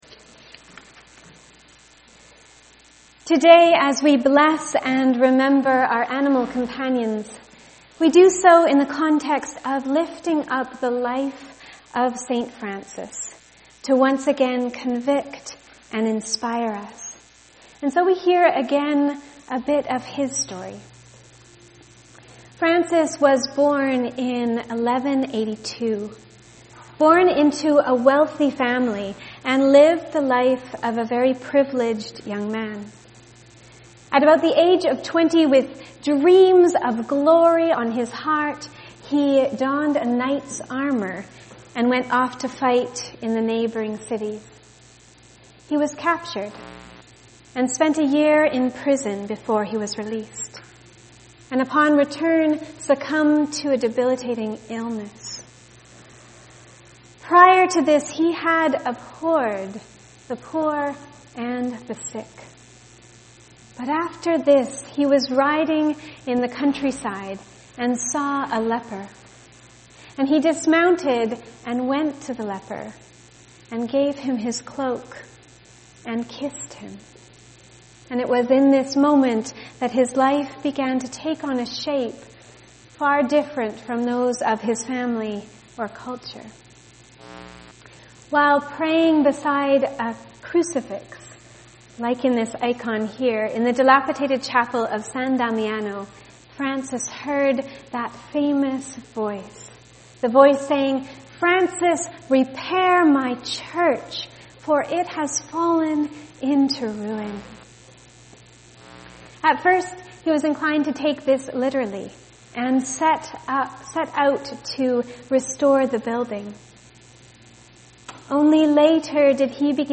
Sermons | the abbeychurch